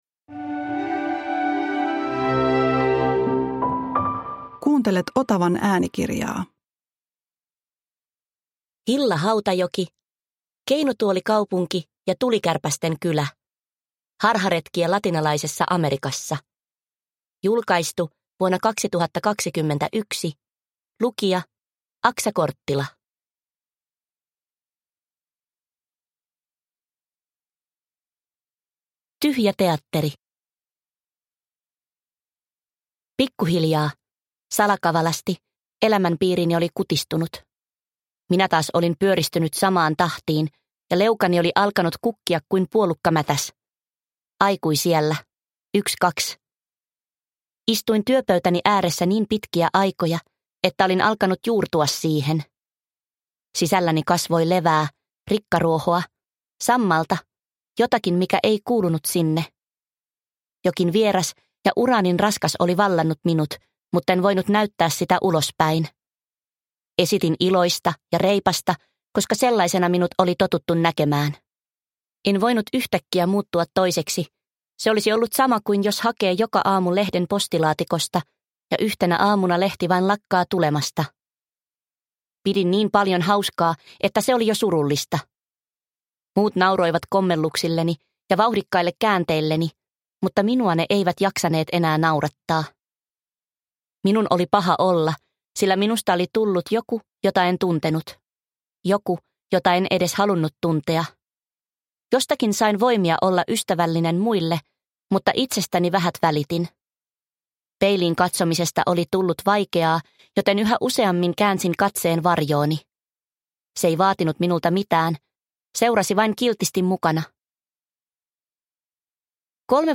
Keinutuolikaupunki ja tulikärpästen kylä – Ljudbok – Laddas ner